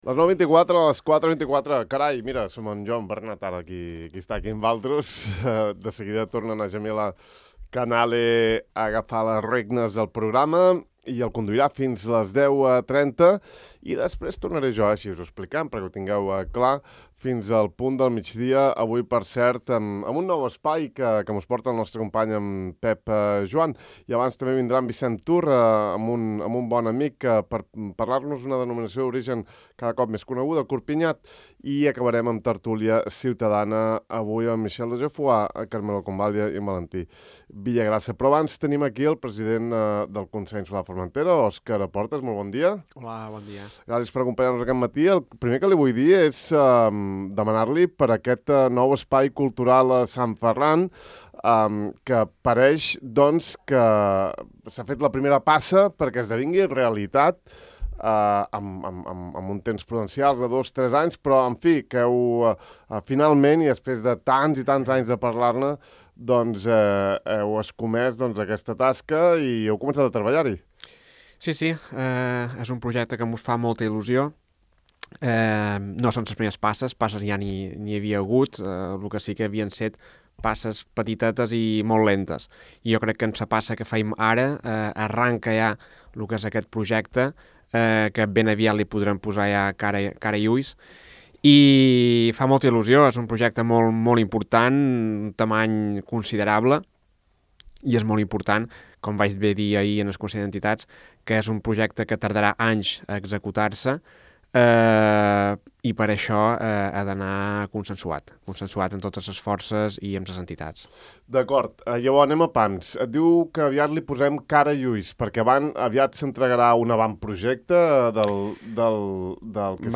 Óscar Portas, president del Consell de Formentera, ha abordat a Ràdio Illa el projecte per definir el futur de l'Espai Cultural de Sant Ferran, que ahir va protagonitzar un dels punts de l'ordre del dia del Consell d'Entitats.